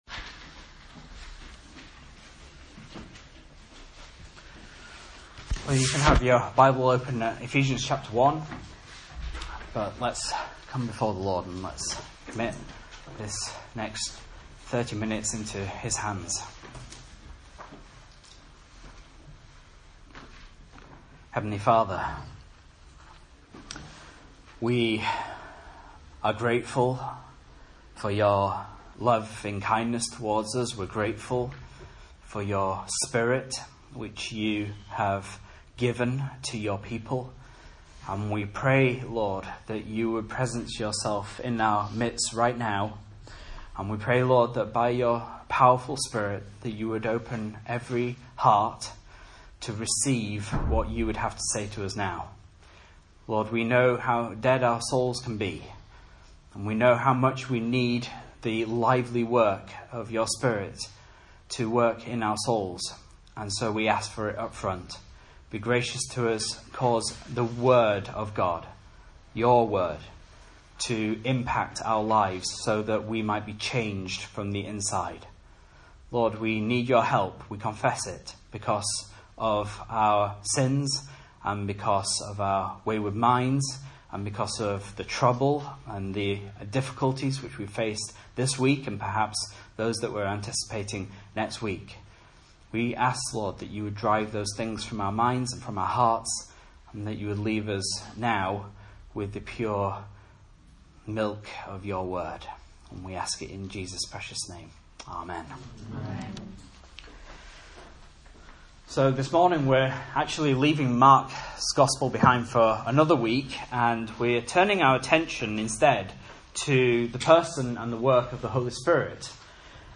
Message Scripture: Ephesians 1:13-14 | Listen